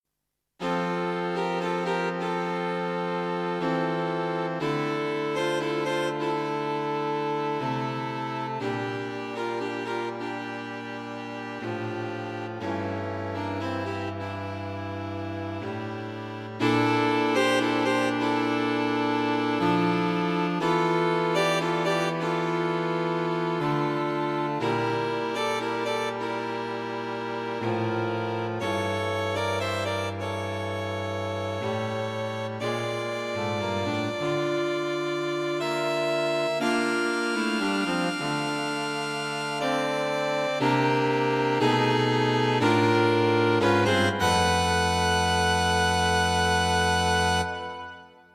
String Quartet for Concert performance
Strength in the form of dissonance.